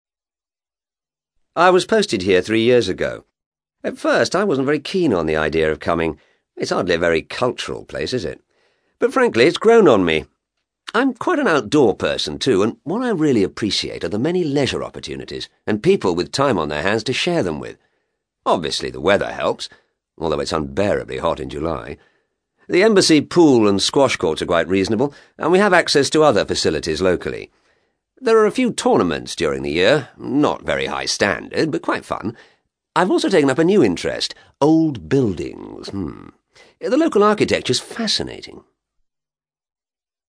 ACTIVITY 112: You will hear five short extracts in which British people are talking about living abroad.